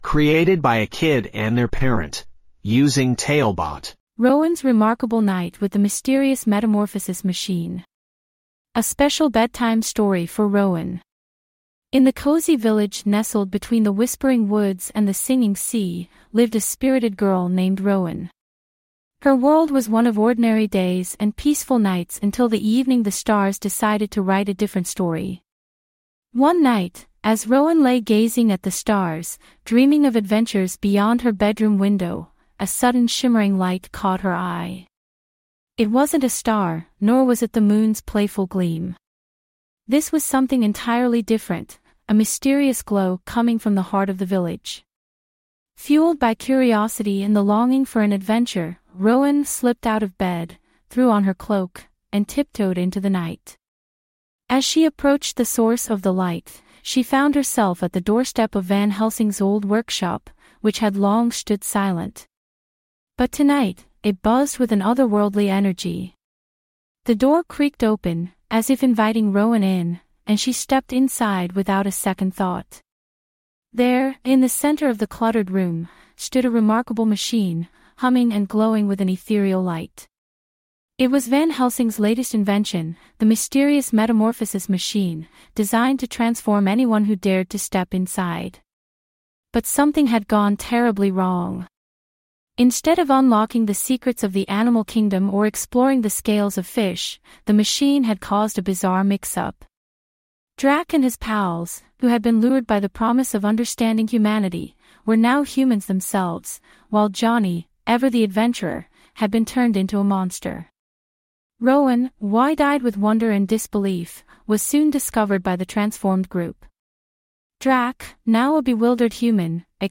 5 minute bedtime stories.
TaleBot AI Storyteller